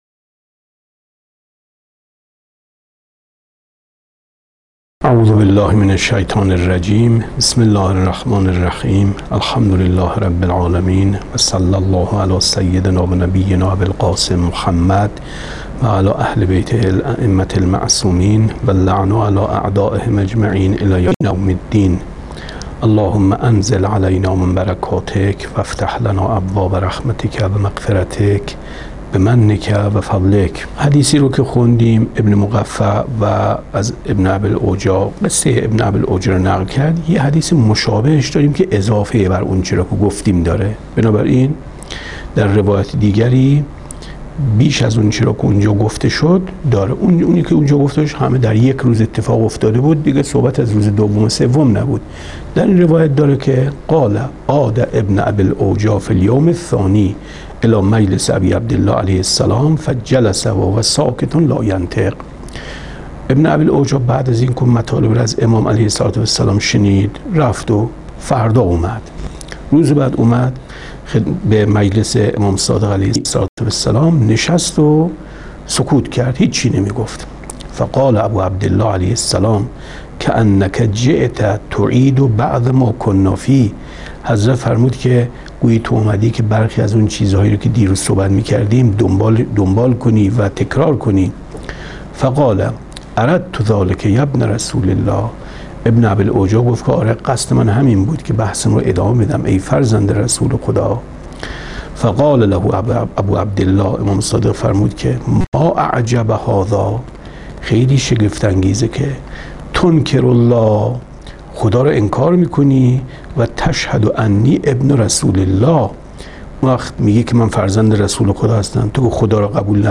کتاب توحید ـ درس 10